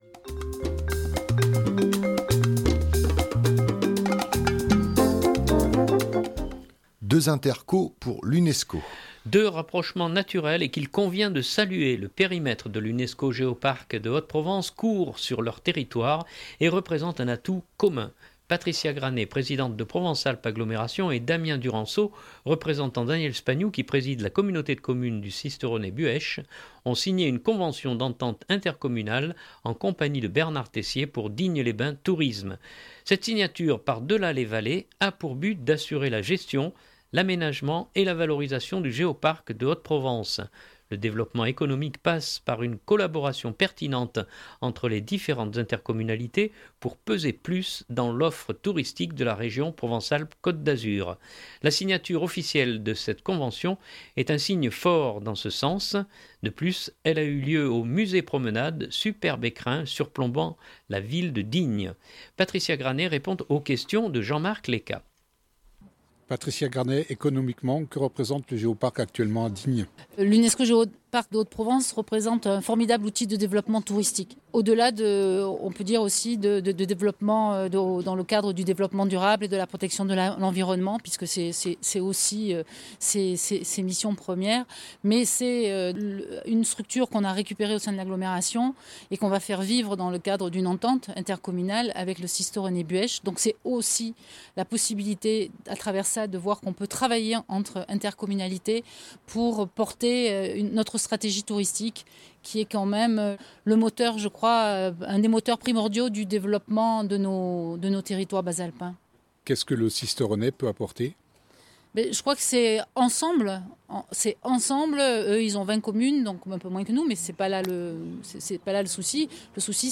De plus elle a eu lieu au Musée promenade, superbe écrin surplombant la ville de Digne.